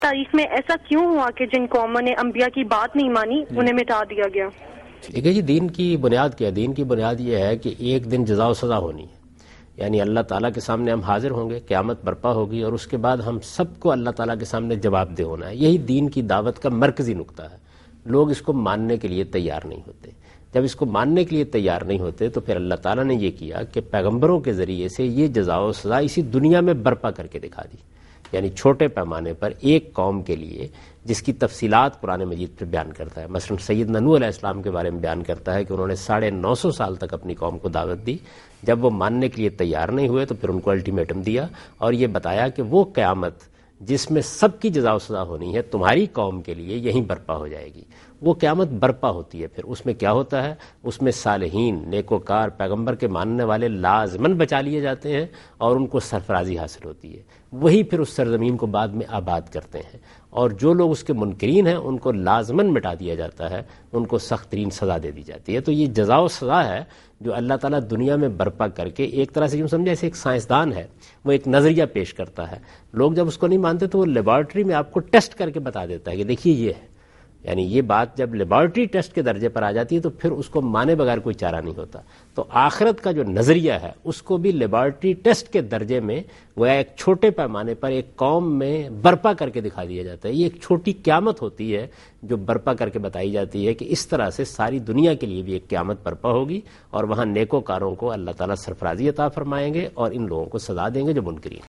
Answer to a Question by Javed Ahmad Ghamidi during a talk show "Deen o Danish" on Duny News TV